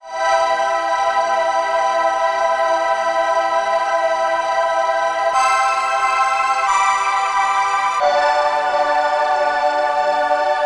标签： 90 bpm Chill Out Loops Pad Loops 1.79 MB wav Key : C
声道单声道